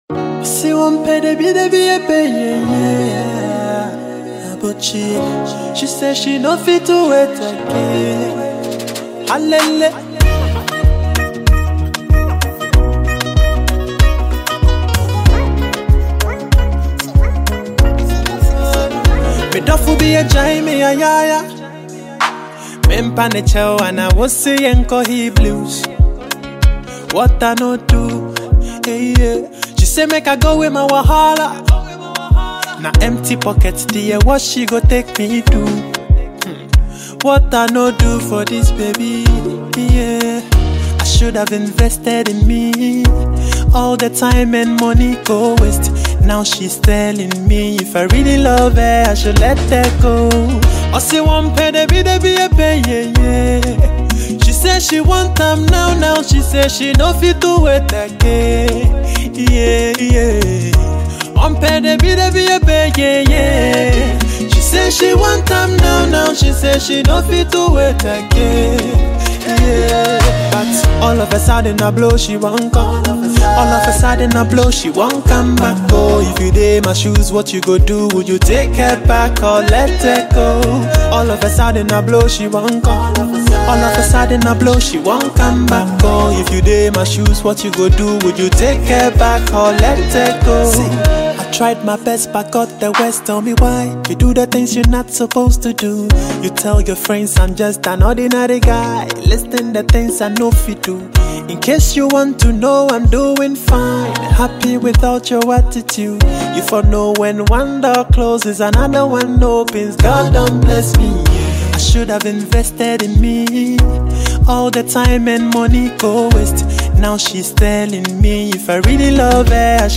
Ghanaian Afropop/Highlife Artiste